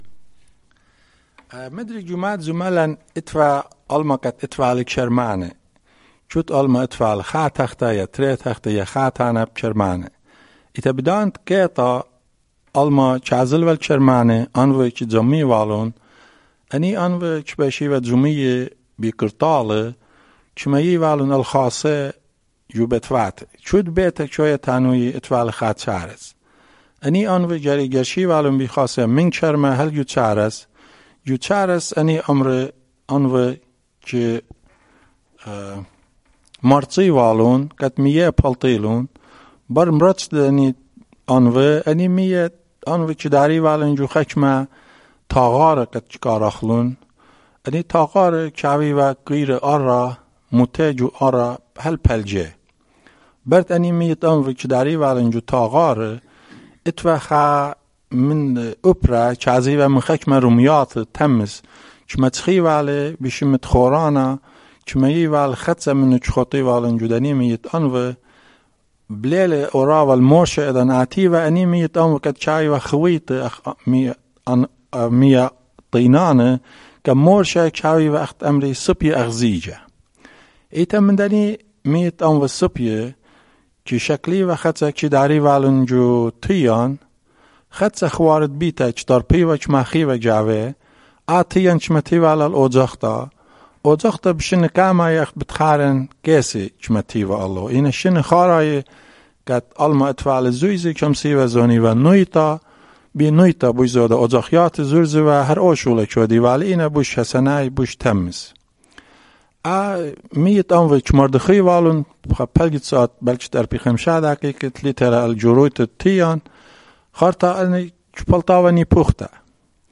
Urmi, Christian: Nipuxta